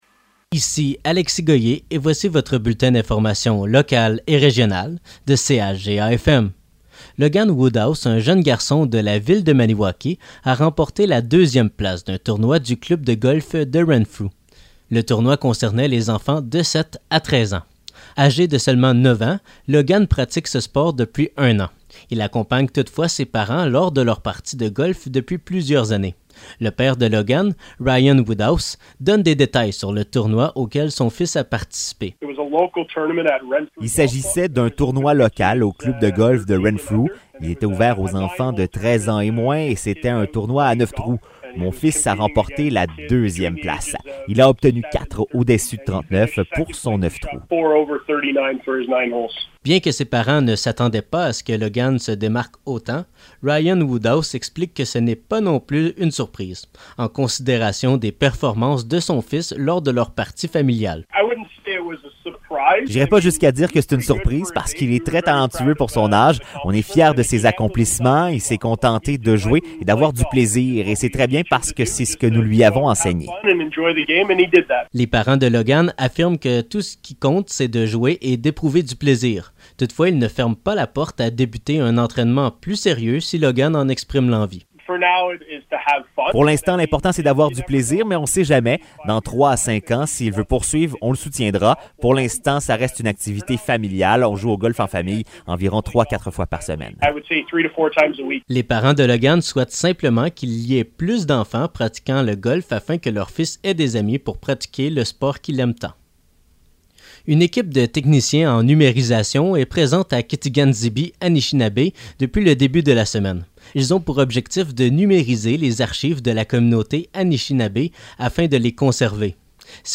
Nouvelles locales - 11 août 2023 - 12 h